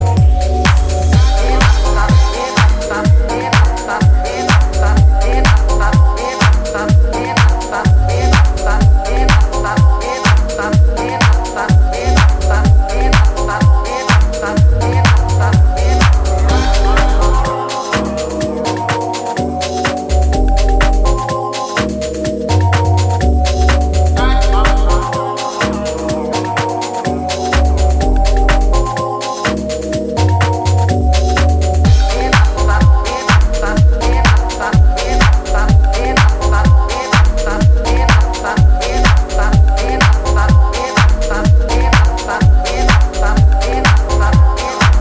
ジャズキーを旋回させながらフロアを掻き乱す